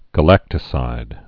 (gə-lăktə-sīd)